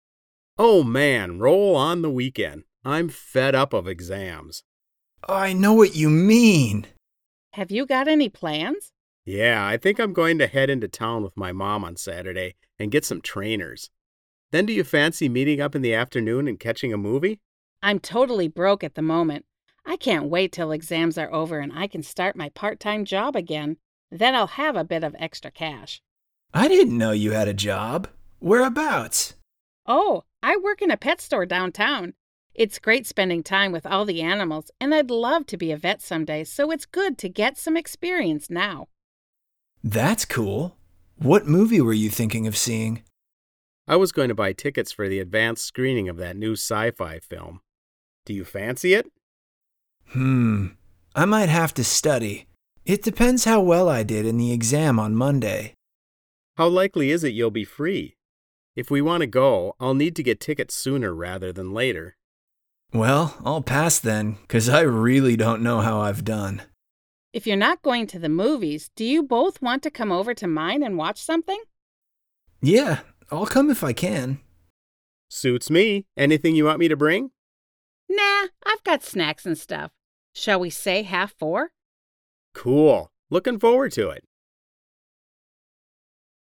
RH5 9.2_conversation.mp3